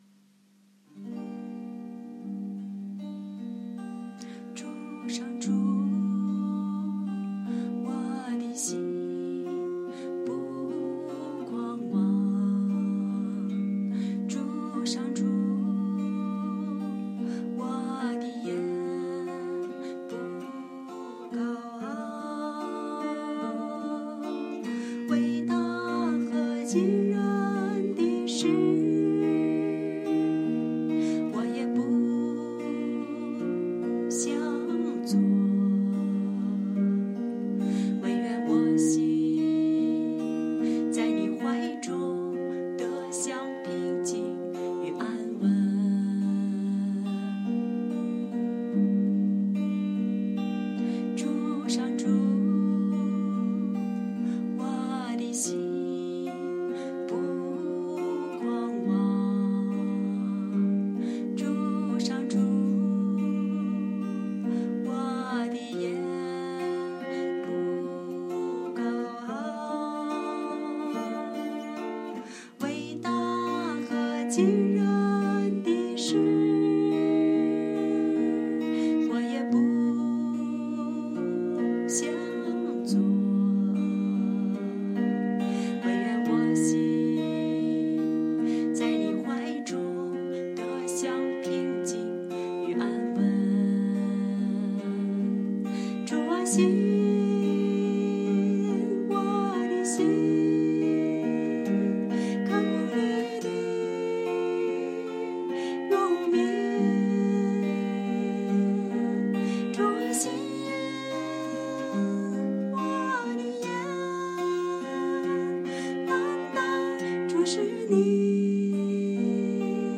首页 / 音乐/ 全球华语圣歌大赛